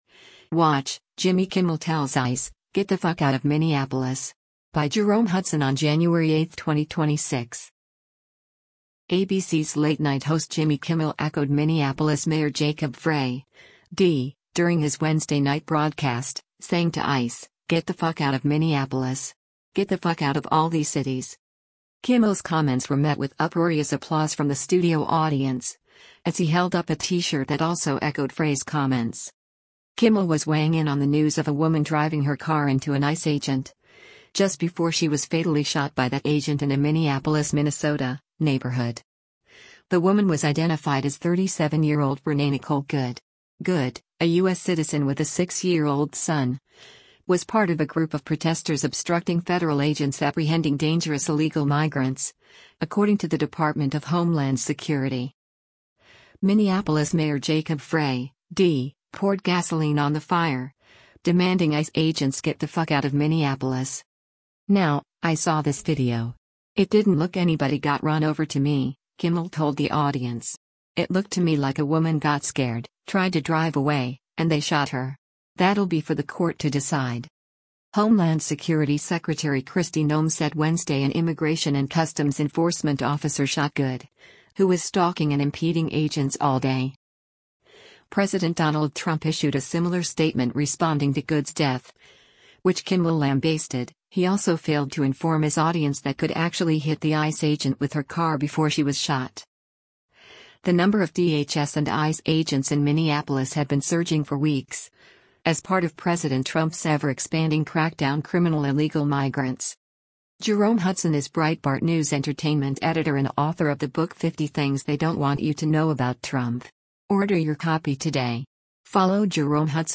Kimmel’s comments were met with uproarious applause from the studio audience, as he held up a t-shirt that also echoed Frey’s comments.